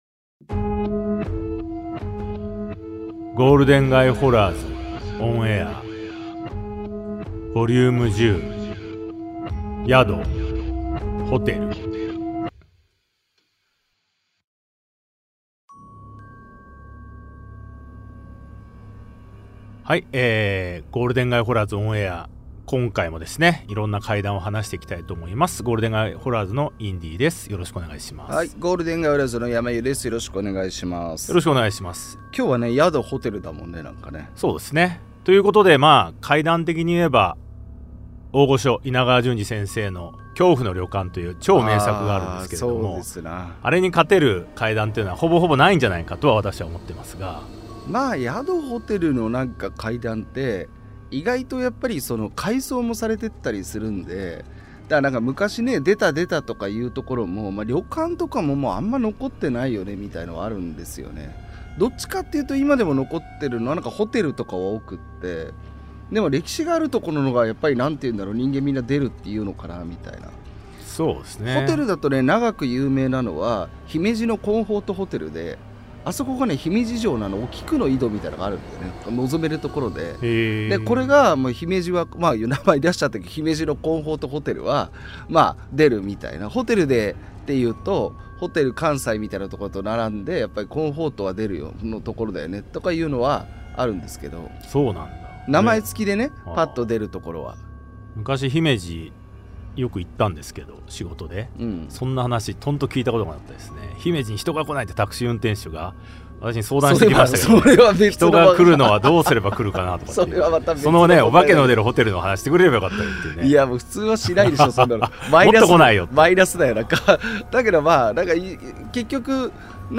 対談形式のホラー番組。